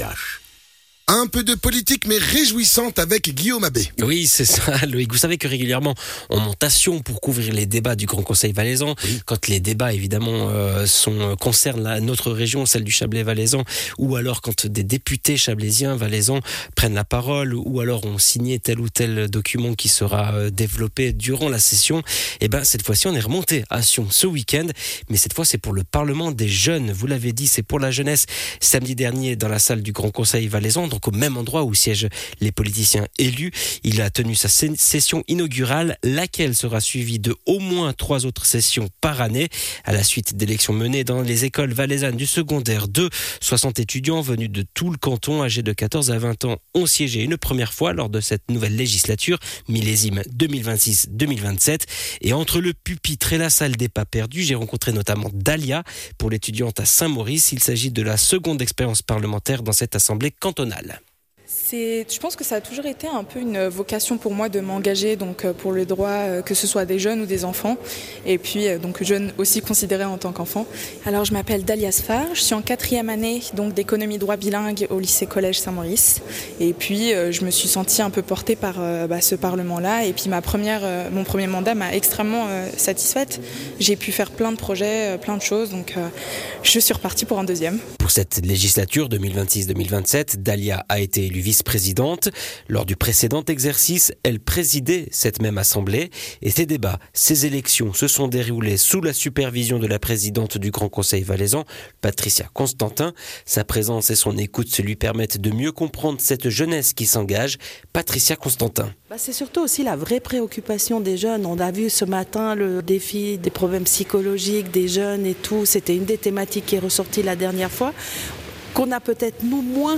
Intervenant(e) : Multi-intervenants
Le Parlement des jeunes du Valais est de retour. Il a siégé samedi matin dans la salle du Grand conseil à Sion pour sa session inaugurale.